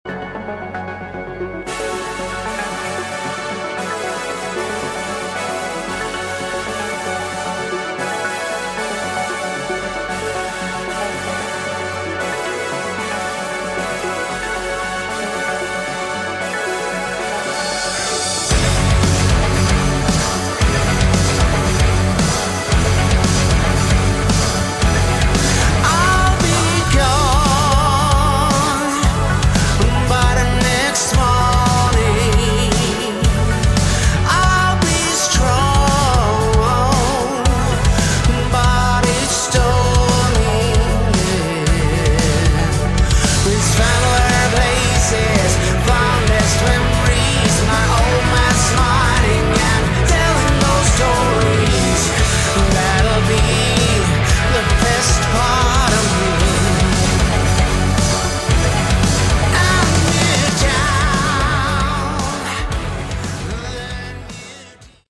Category: Hard Rock
vocals
guitars, keyboards, programming
organ
bass
drums